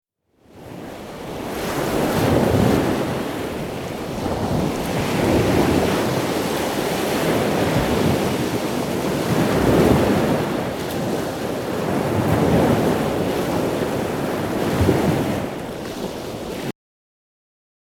Gorgeous, and what a powerful sound, too.
surf.mp3